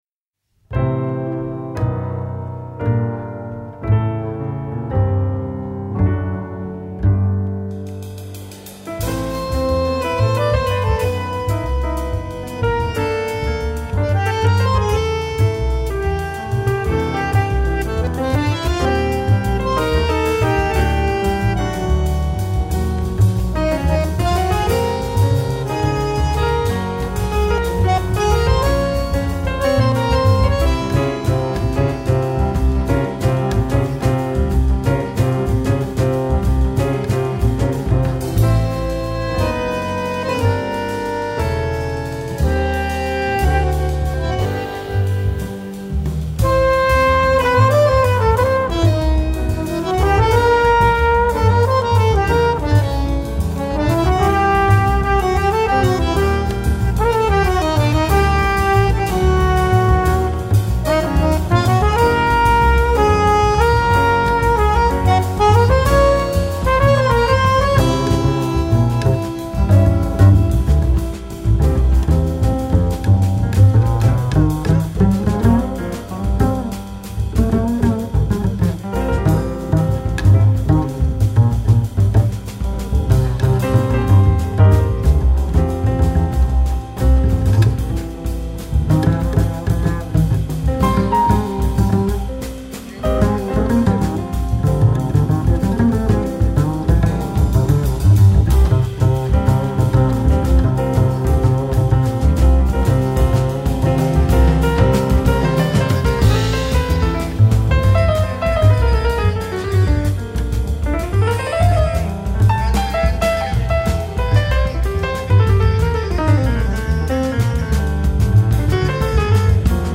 Jazz / World.
bandoneon
flugelhornist
drummer